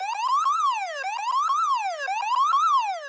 emergency.ogg